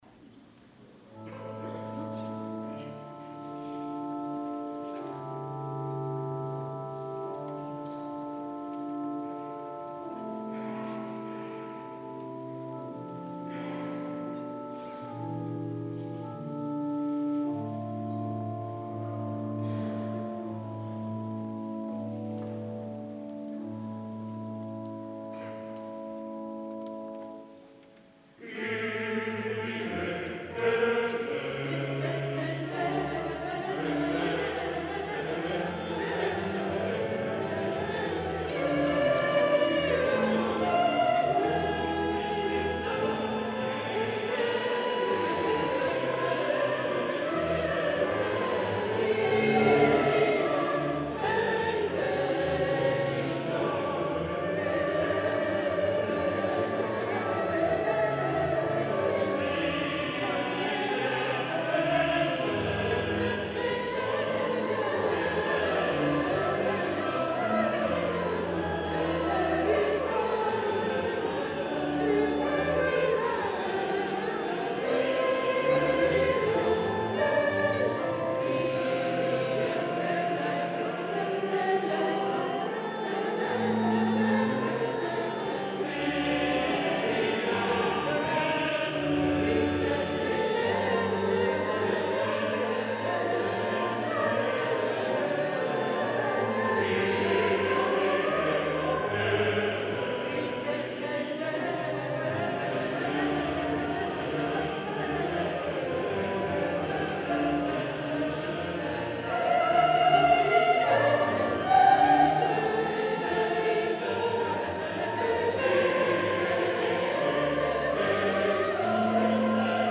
S. Gaudenzio church choir Gambolo' (PV) Italy
22 dicembre 2025 - Concerto di Natale
audio del concerto